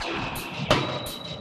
Index of /musicradar/rhythmic-inspiration-samples/170bpm